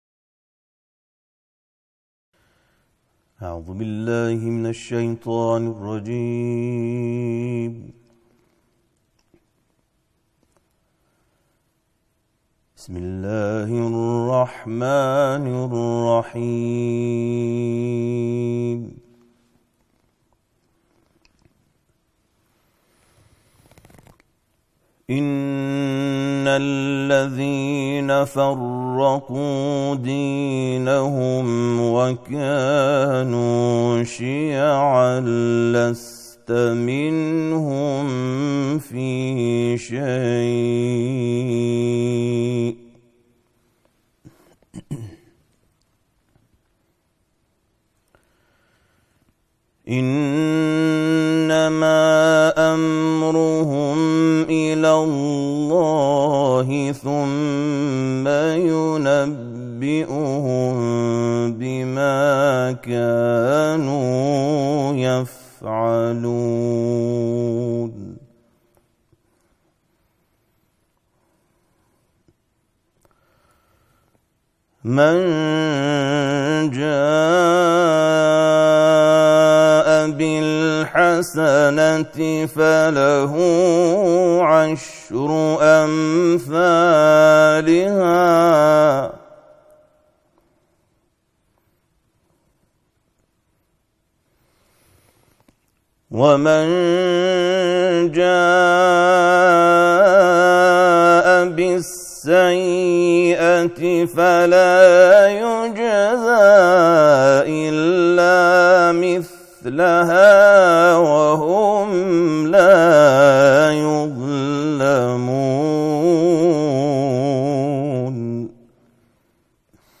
به گزارش ایکنا، همزمان با ایام ماه مبارک رمضان، طرح ۳۰ کرسی تلاوت قرآن در ۳۰ شب ماه بهار قرآن به صورت مجازی و با حضور قاریان ممتاز و بین‌المللی از سوی فرهنگ‌سرای قرآن اجرا شد.